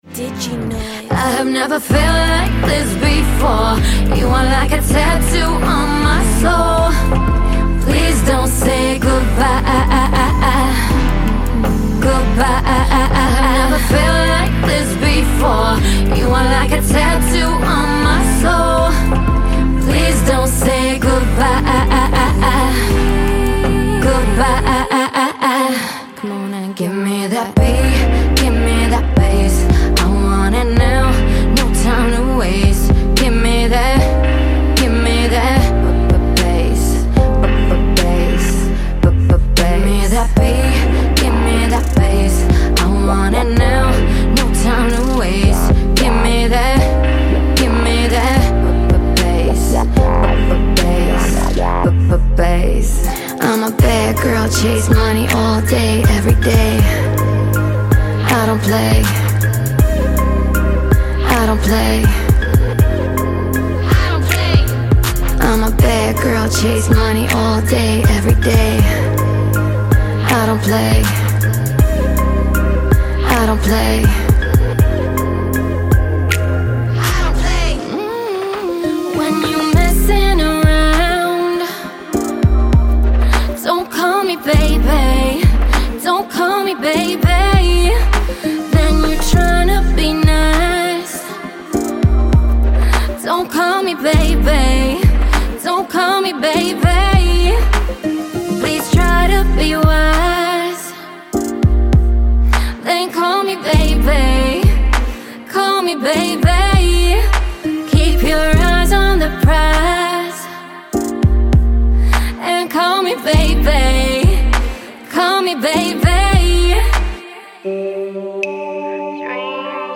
Fresh, super melodic, and incredibly inspiring!
•276 Vocalizations & Vocal Sequences.
Demo